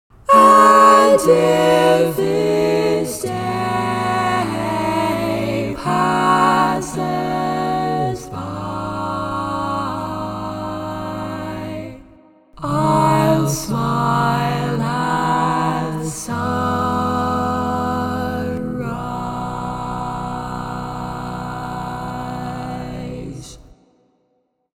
Key written in: B Major
How many parts: 4
Type: SATB
All Parts mix: